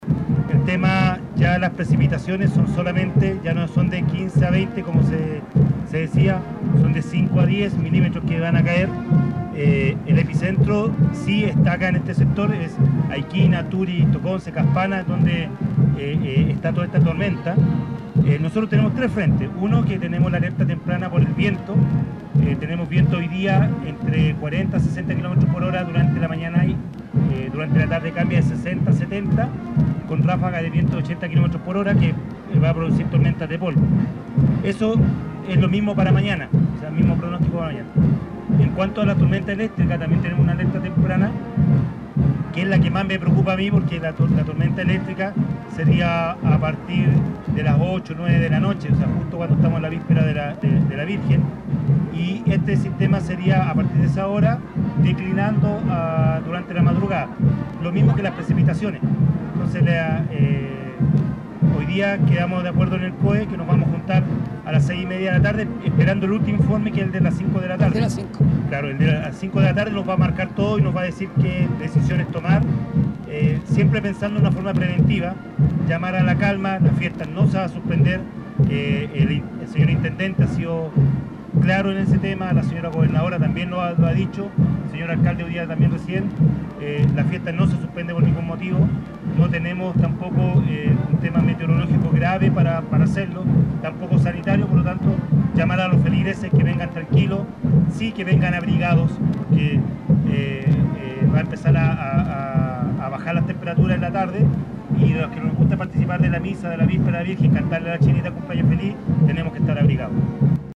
RODOLFO CARUS ENCARGO DE PROTECCION CIVIL Y EMERGENCIA – GENTILEZA RADIO MARIA REINA